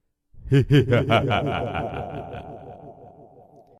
evil-laughing-sound-effects-halloween-sound-no-copyright.mp3